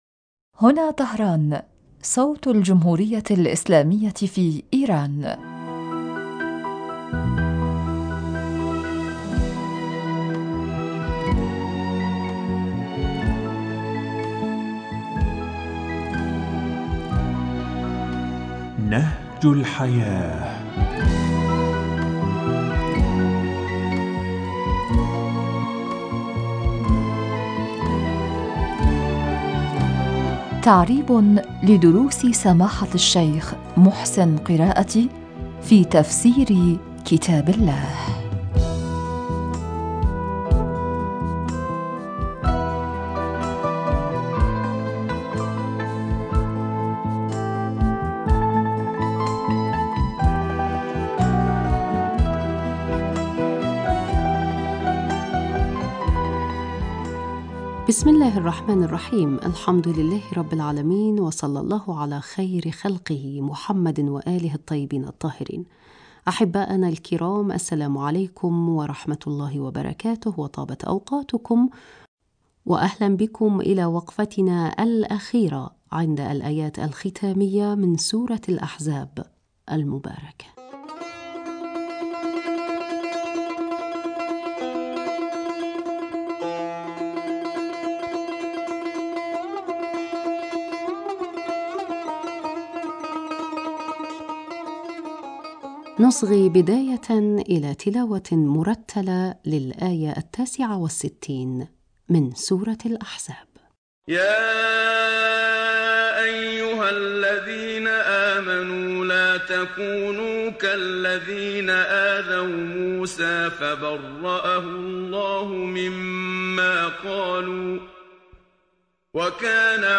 نصغ بداية إلى تلاوة مرتلة للایة التاسعة والستین منها: